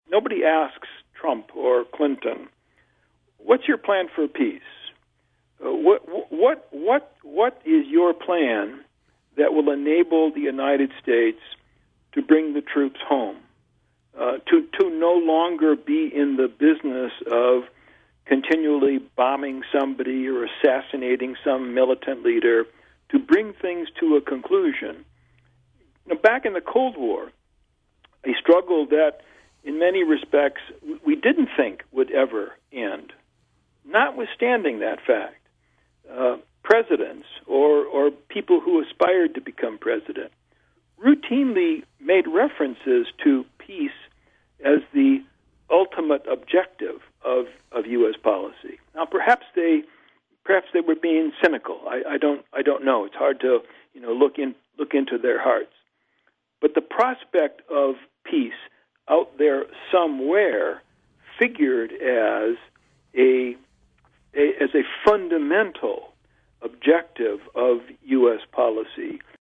In-Depth Interview: Prof. Andrew Bacevich on the Long Arc of America’s Mideast Wars